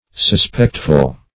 Meaning of suspectful. suspectful synonyms, pronunciation, spelling and more from Free Dictionary.
Suspectful \Sus*pect"ful\, a.